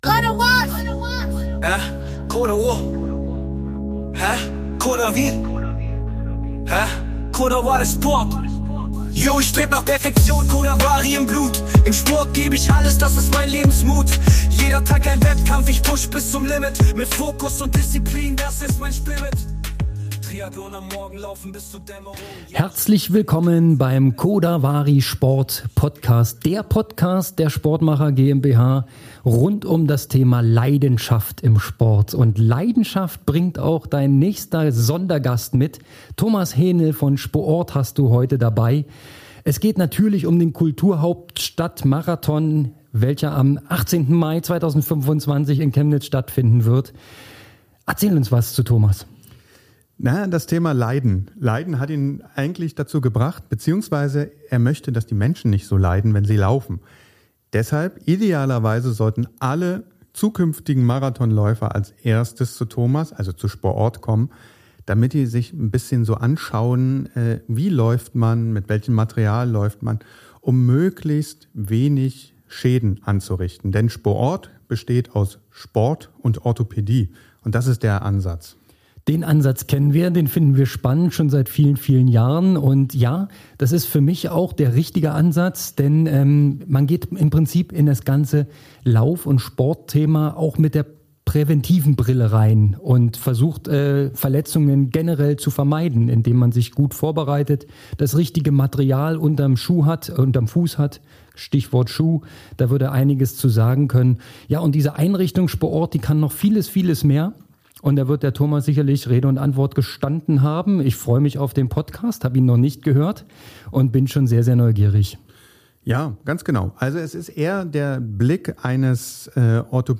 Ein Gespräch über die Feinheiten des Laufsports und wie man seine Ziele erreicht – ohne sich zu verletzen.